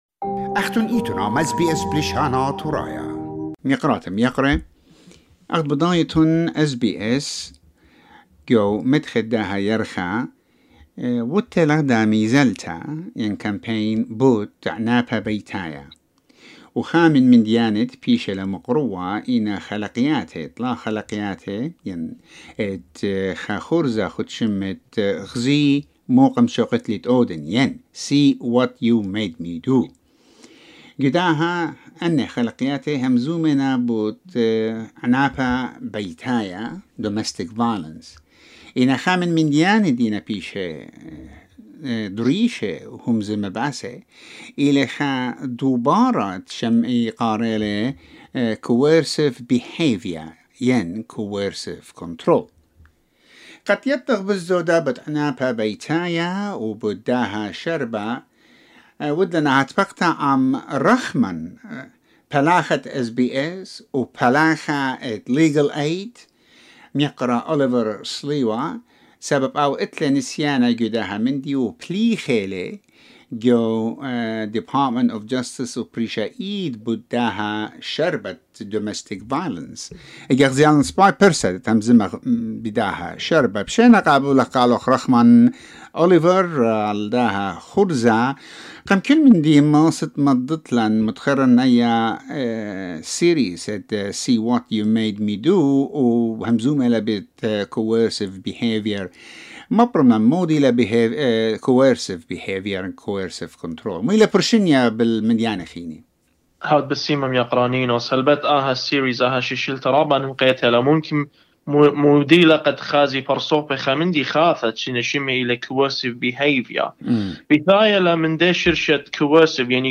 Watch SBS documentary series about domestic violence (See what you made me do) Source: Getty Images/Medioimages/Photodisc In this interview, we also learn about the different forms of domestic and family violence and the types of relationships where family violence occurs.